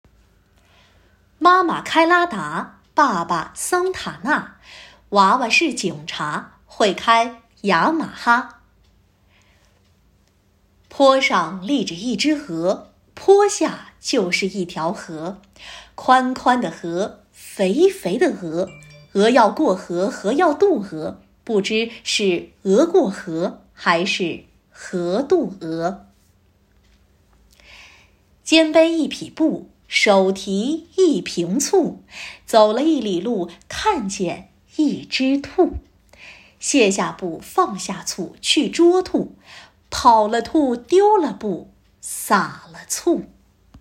领读课件
课件文本（绕口令）